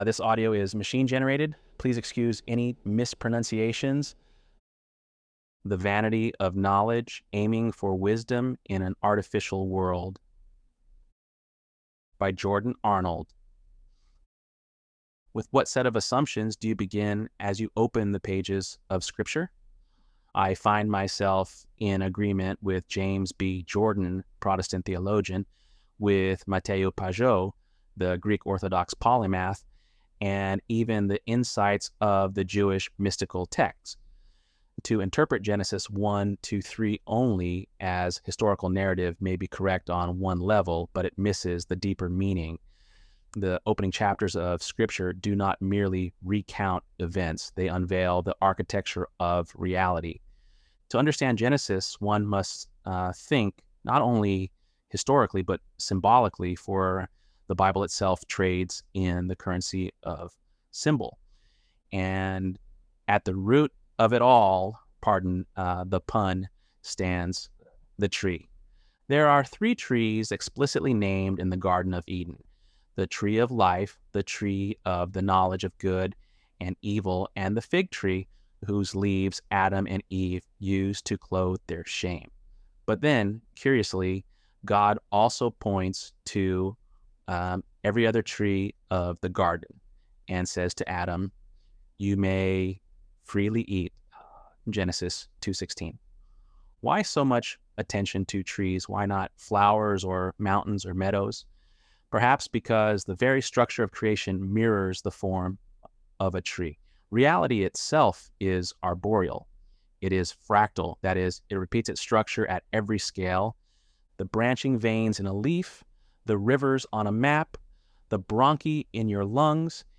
ElevenLabs_11_-1.mp3